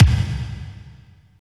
32.02 KICK.wav